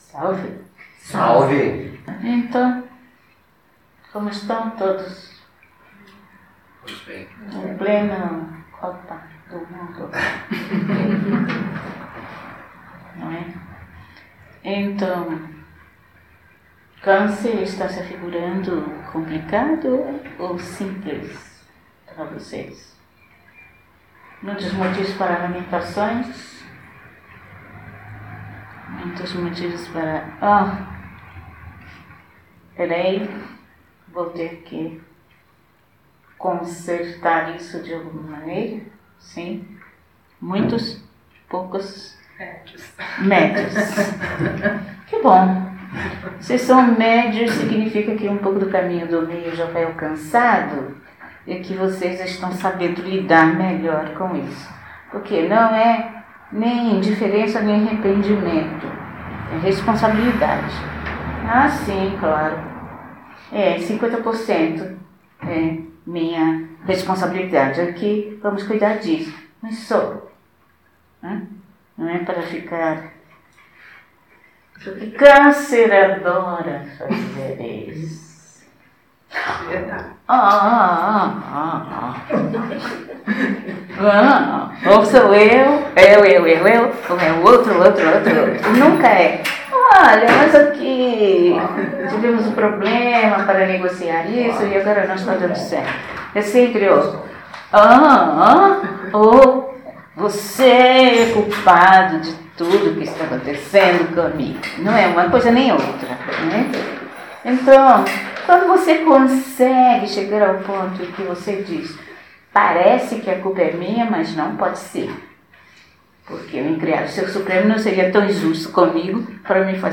Perguntas e respostas